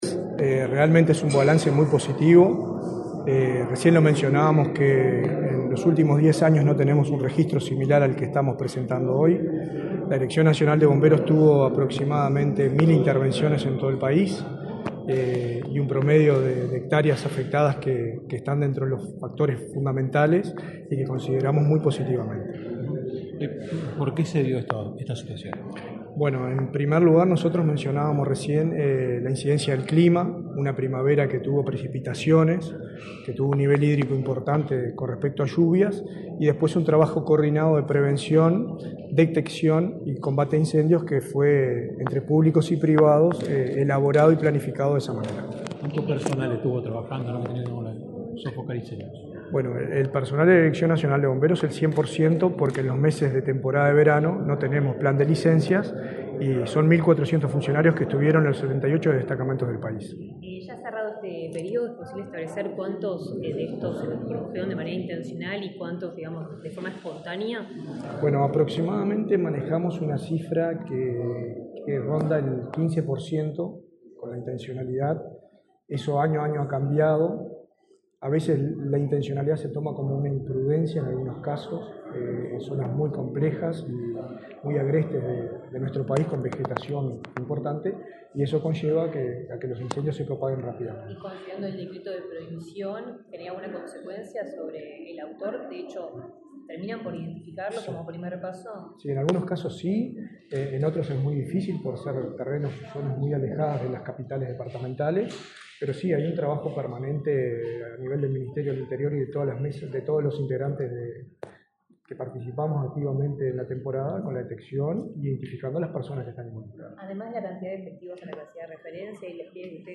Declaraciones del director nacional de Bomberos, Richard Barboza
Este lunes 6 en la Torre Ejecutiva, el titular de la Dirección Nacional de Bomberos, Richard Barboza, dialogó con la prensa, luego de participar en el